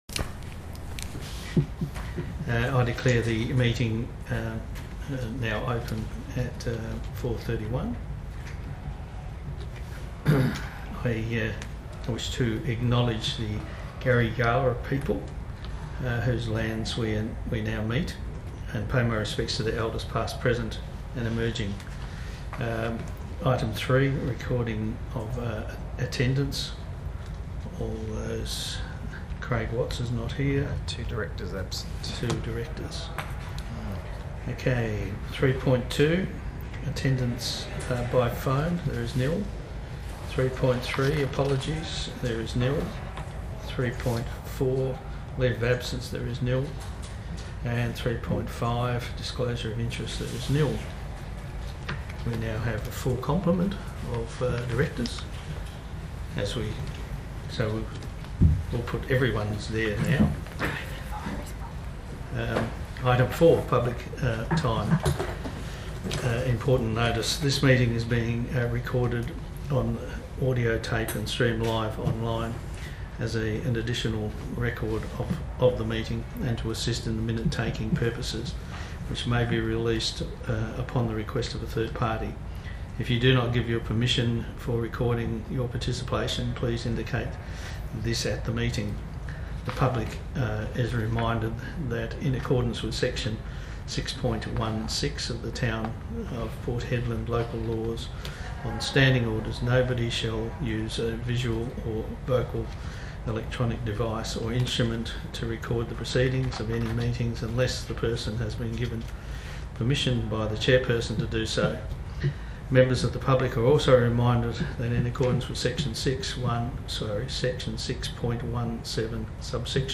Special Council Meeting - 18 March 2020 » Town of Port Hedland